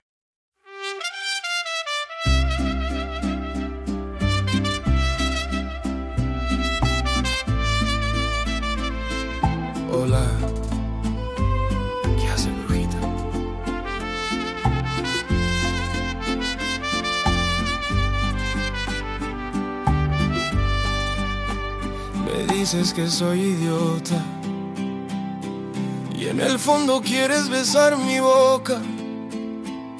Un viaje sonoro emocionalmente cargado.